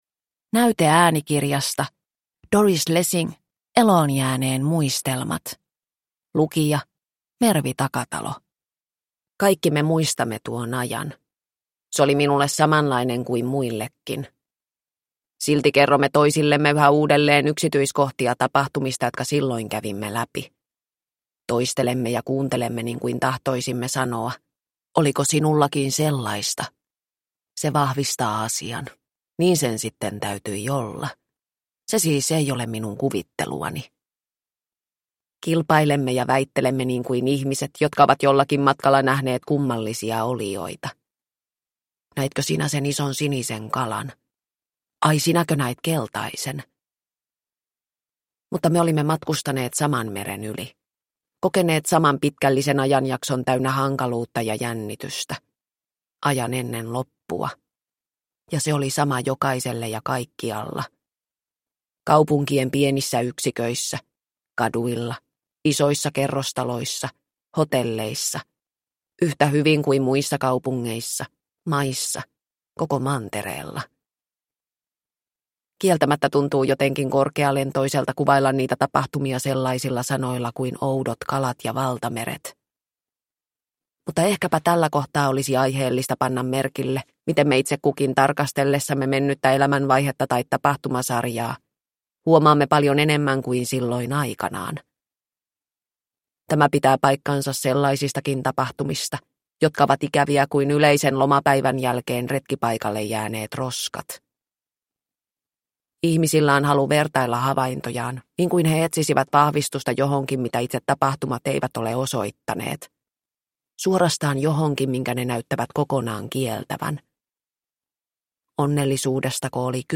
Eloonjääneen muistelmat – Ljudbok – Laddas ner